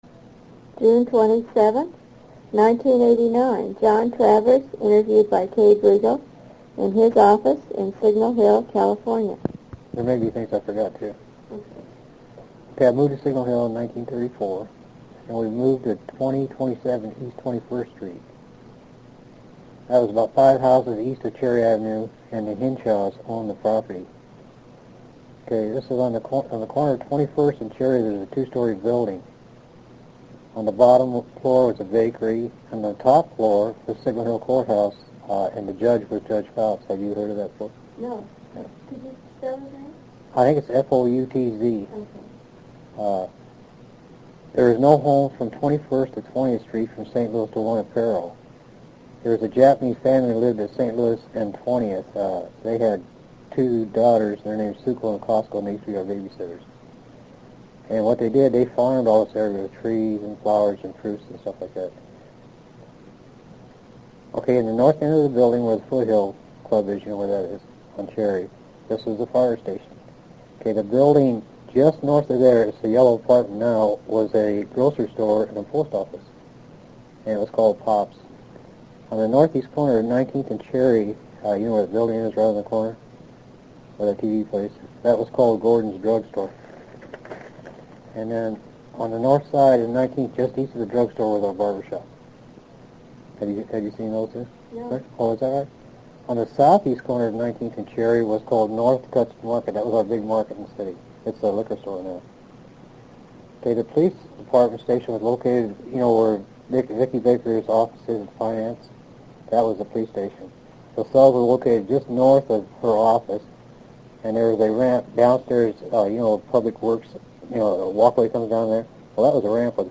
audio interview #1 of 1
interviewed in an office in the Signal Hill City Yard. TOPICS - family background; Japanese families; 1933 Long Beach earthquake; Kid Mexico; WWII; military...